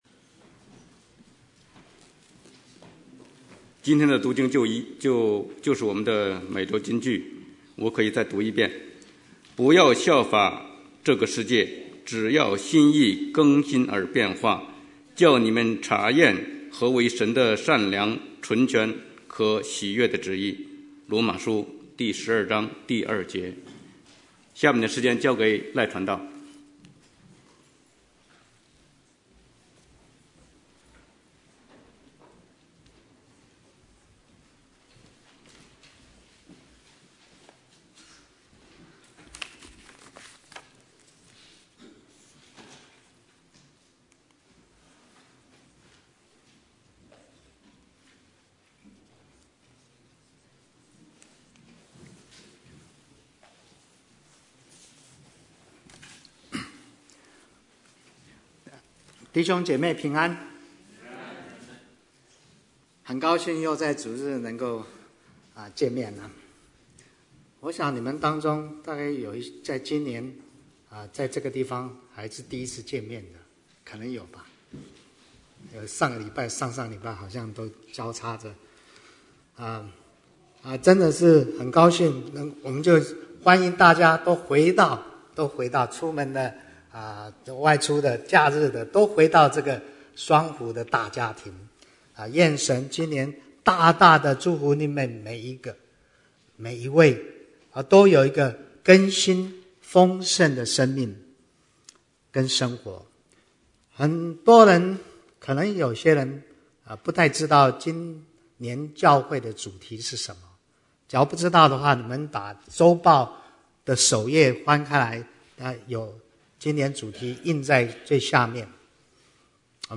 Bible Text: 羅馬書12:2 | Preacher